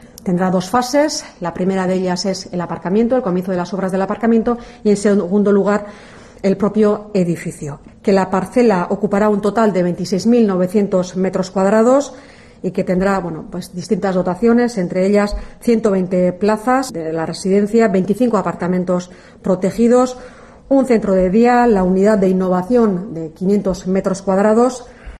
Eider Mendoza, portavoz de la Diputación de Gipuzkoa